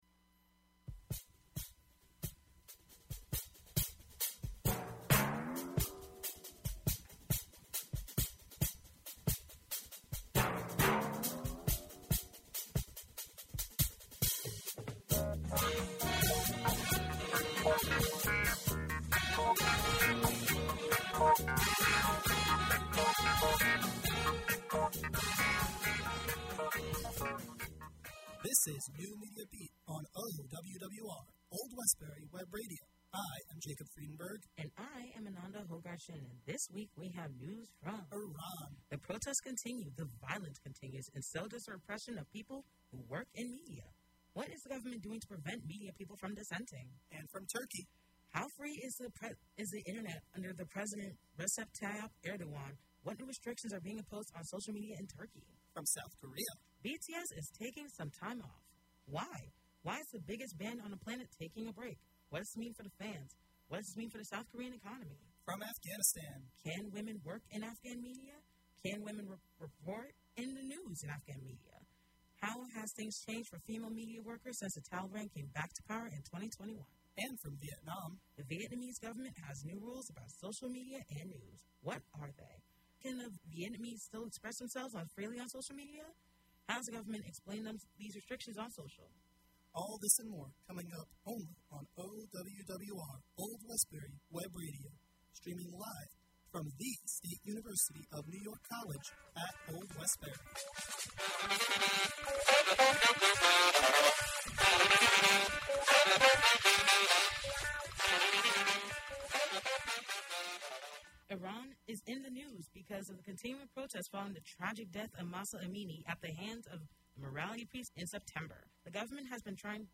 The NMB Podcast streams live on Old Westbury Web Radio Thursdays from 10:00-11:00 AM EST.